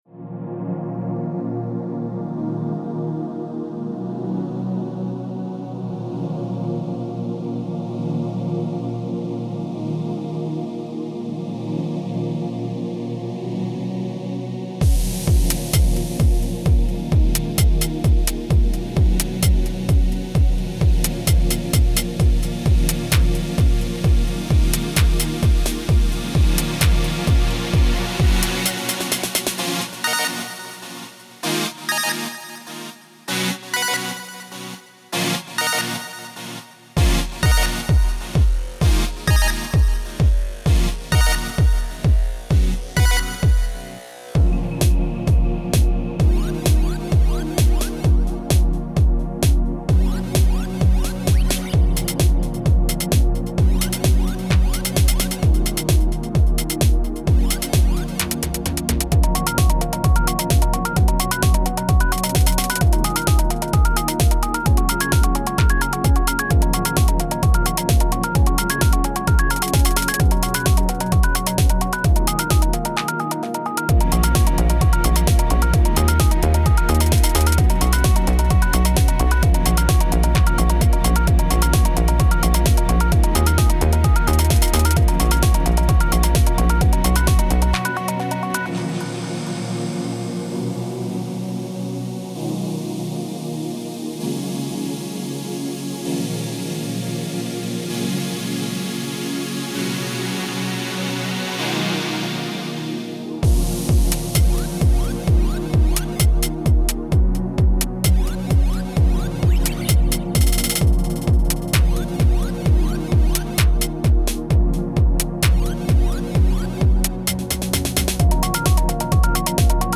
Electronic / Retro / 2000s